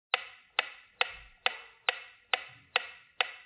Metronomo
Suono di metronomo meccanico. Tempo 4 quarti, 2 misure loopabili.
Metronome-02.mp3